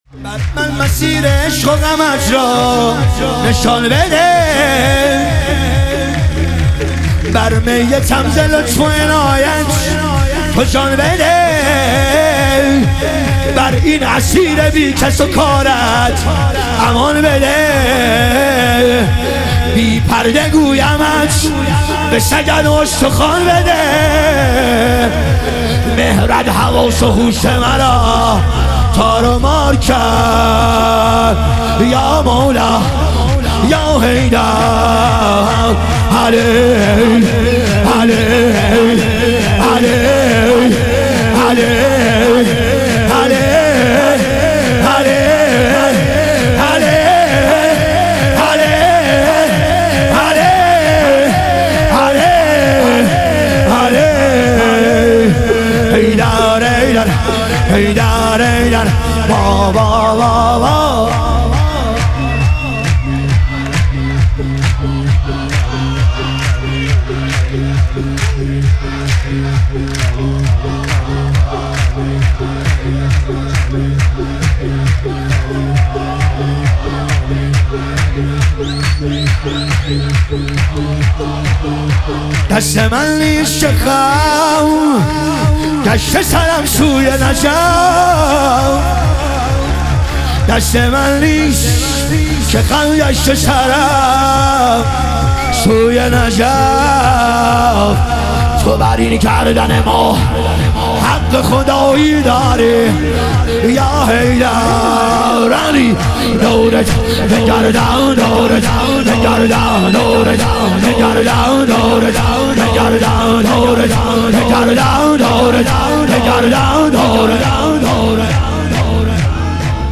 شور
شب ظهور وجود مقدس حضرت رقیه علیها سلام